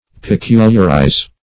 Search Result for " peculiarize" : The Collaborative International Dictionary of English v.0.48: Peculiarize \Pe*cul"iar*ize\, v. t. [imp.